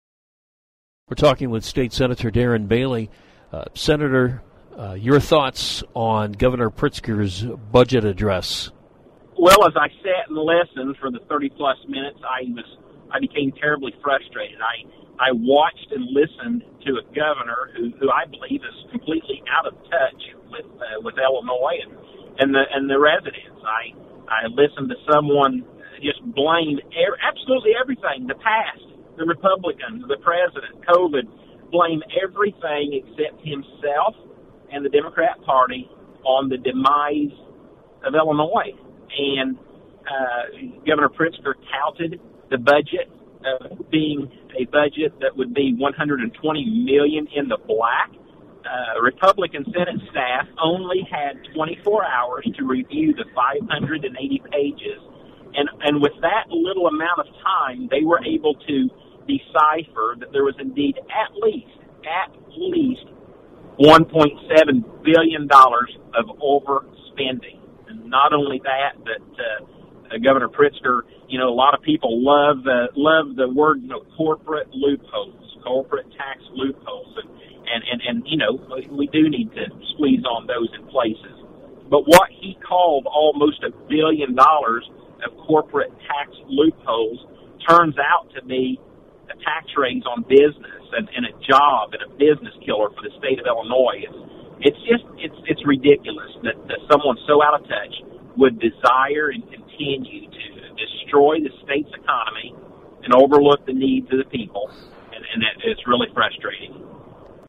Sen.-Darren-Bailey-react-to-Governors-budget-address-2-17-21.mp3